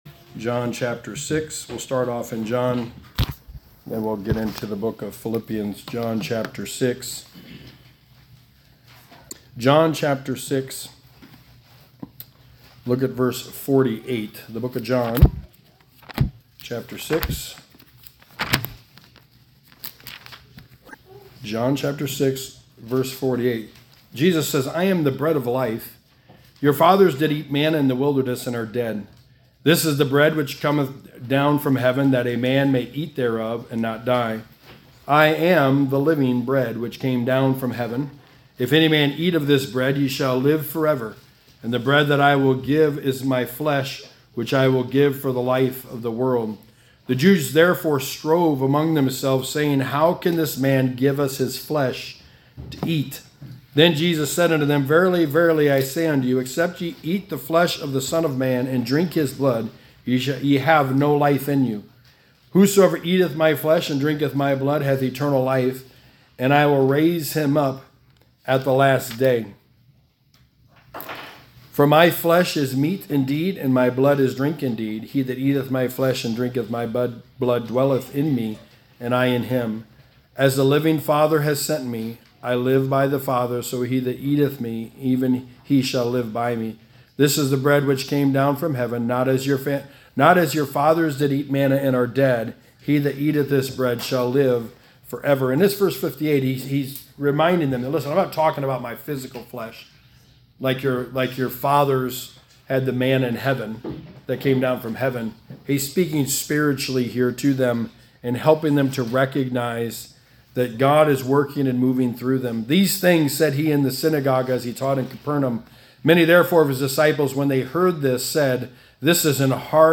Sermon 14: The Book of Philippians: Holding Forth the Words of Life
Passage: Philippians 2:16-18 Service Type: Sunday Morning